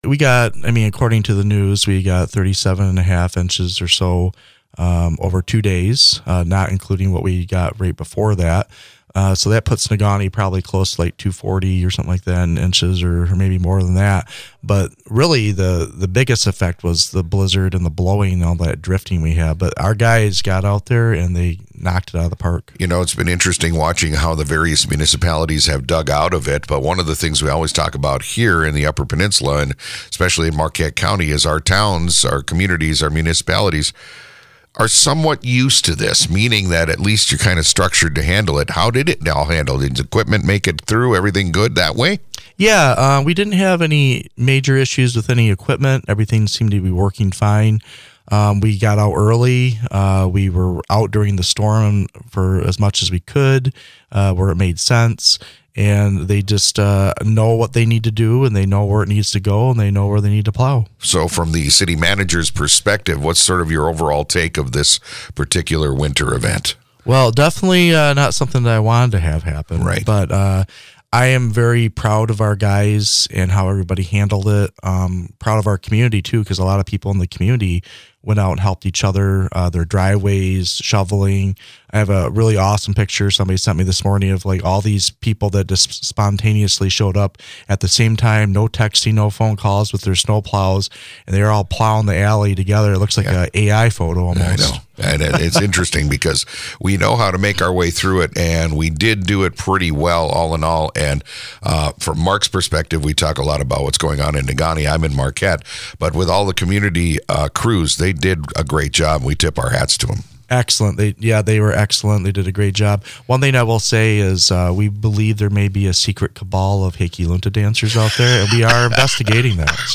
Negaunee City Manager Nate Heffron spoke about the blizzard clean up